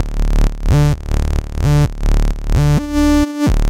酸性琶音
描述：一个不错的琶音，适合酸性音乐。
标签： 130 bpm Acid Loops Synth Loops 637.76 KB wav Key : C
声道立体声